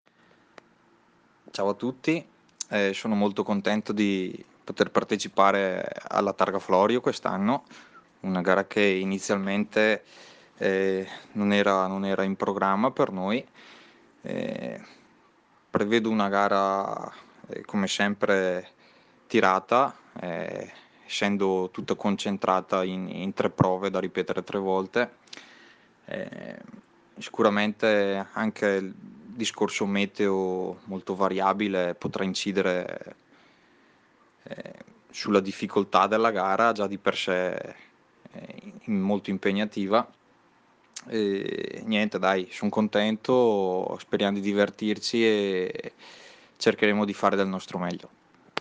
Interviste Rally Targa Florio 2020
Interviste pre-gara